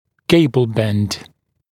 [‘geɪbl bend][‘гейбл бэнд]остроугольный изгиб